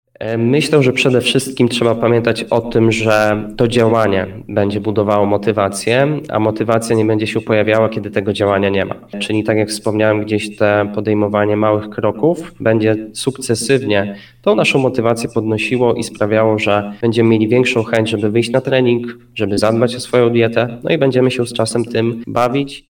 Rozmówca – Dietetyk przy kawie